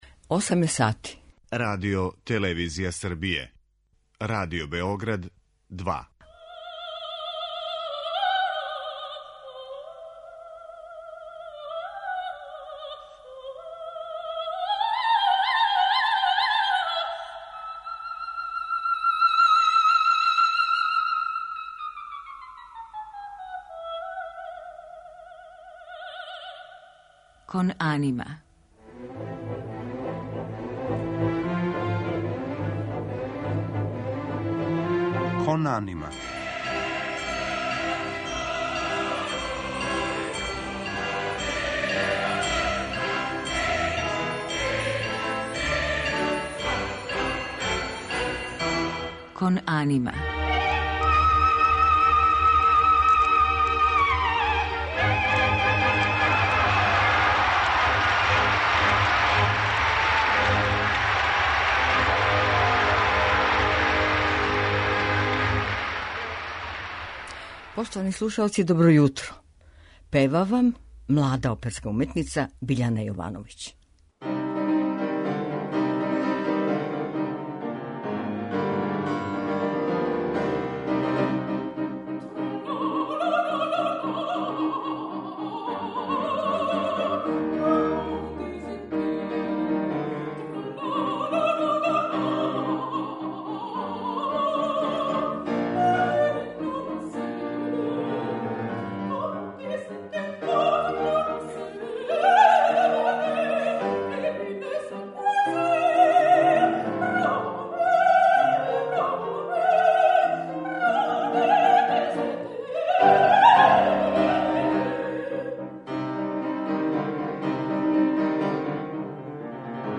млада вокална уметница
У музичком делу биће емитоване арије из опера Бизеа, Штрауса, Глука и Коњовића у њеном извођењу.